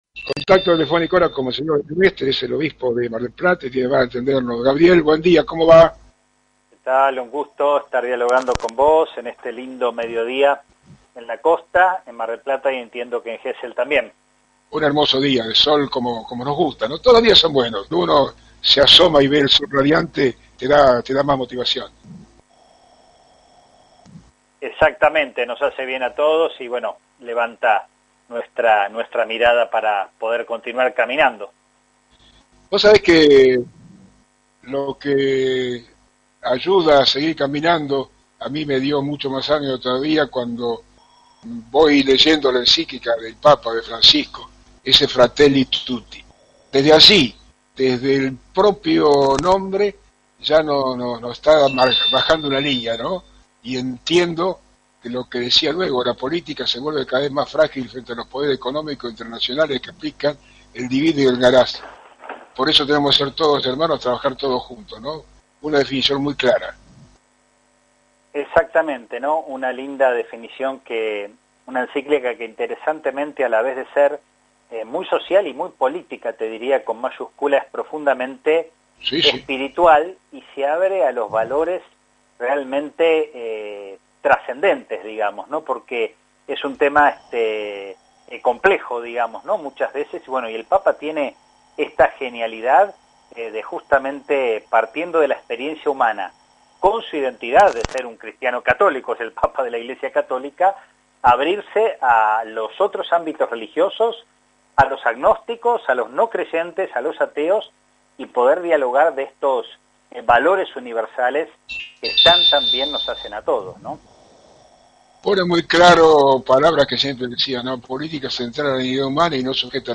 Entrevistado en el programa �El Due�o de la pelota� que se emite en Radio Municipal, Monse�or Mestre habl�, fundamentalmente de la Enc�clica �Fratelli Tutti�.